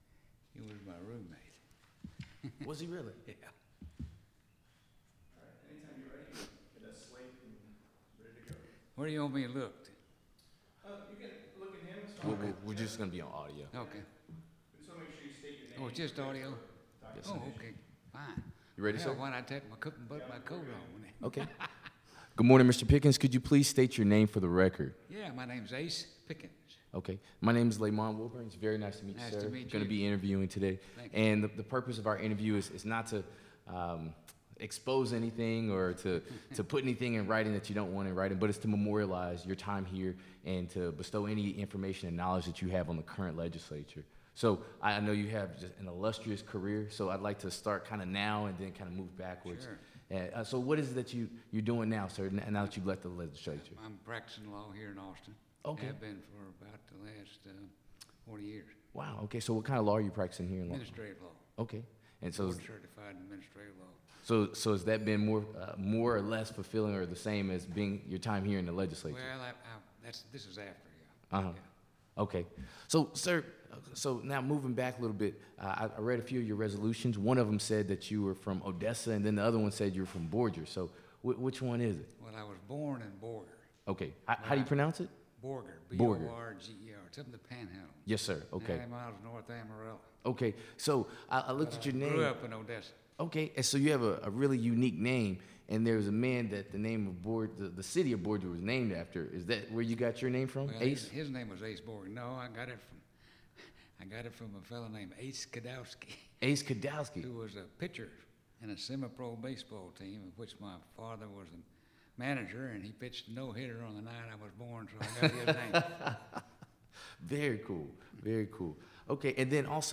Biographical Sketches Oral history interview with Ace Pickens, 2017. Texas House of Representatives .